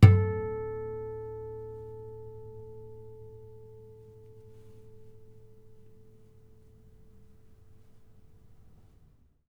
harmonic-05.wav